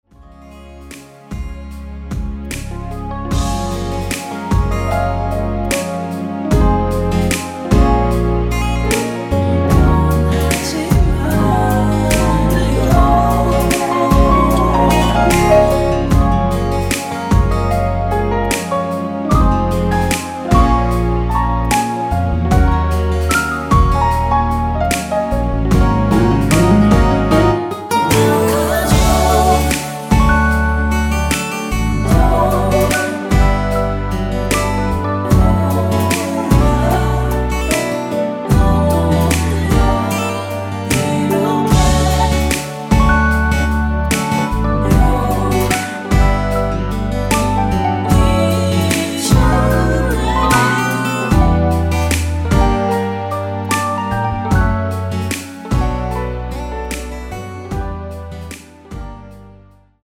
원키에서(-2)내린 코러스 포함된 MR입니다.(미리듣기 참조)
Db
앞부분30초, 뒷부분30초씩 편집해서 올려 드리고 있습니다.
중간에 음이 끈어지고 다시 나오는 이유는